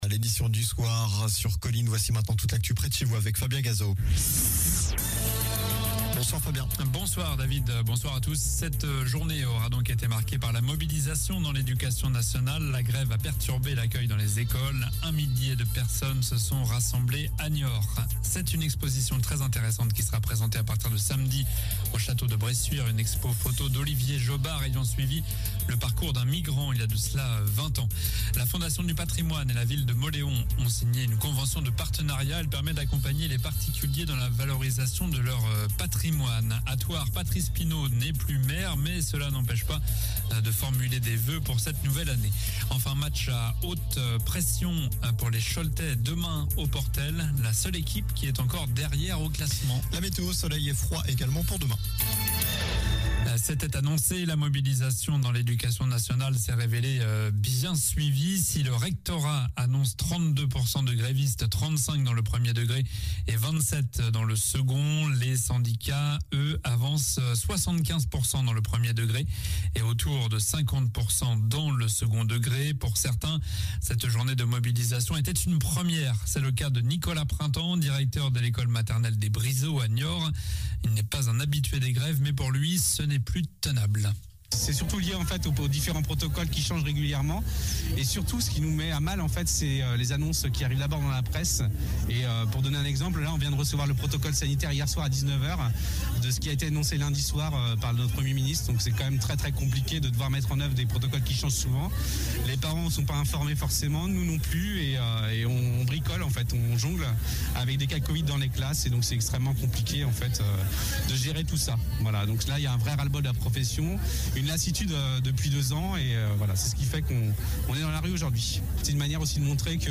Journal du lundi 13 décembre (soir)